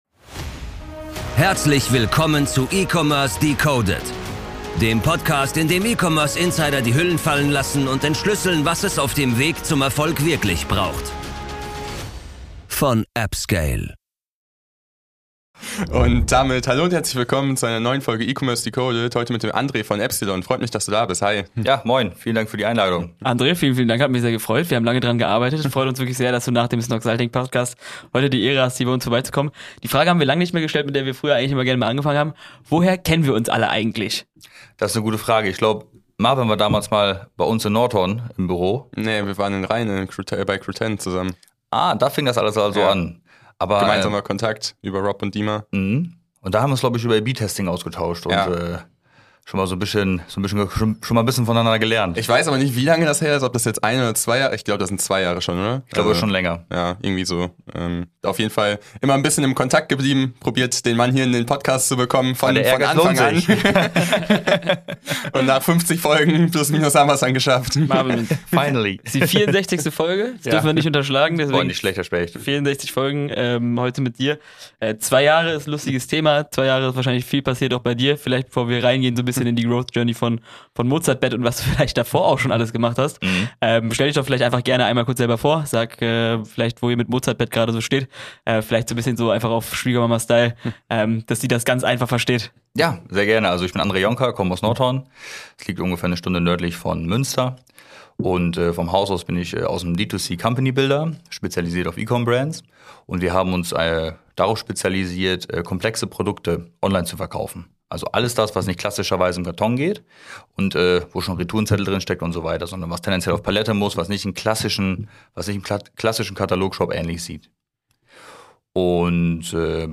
Ein tolles Gespräch über Marketing, Skalierung und Brand Building.